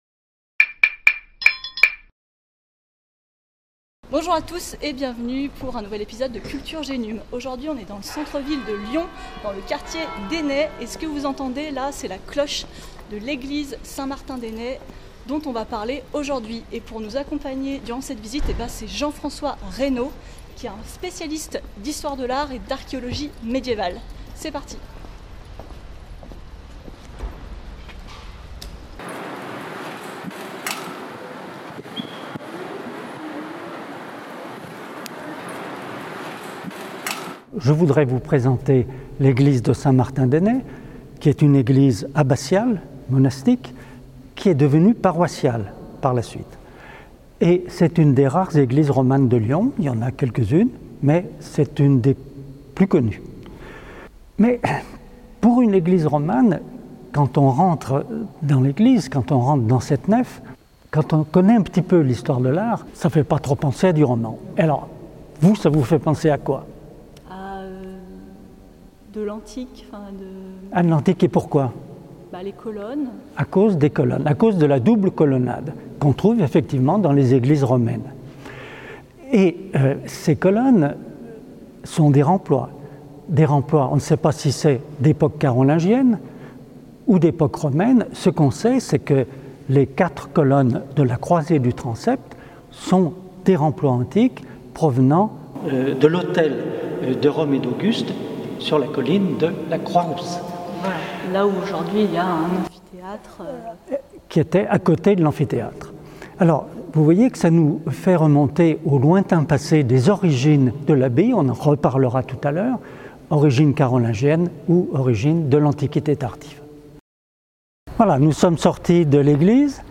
La vidéo, tournée à l'extérieur et à l'intérieur de Saint-Martin d'Ainay, comporte les séquences suivantes : Le clocher-porche (ext.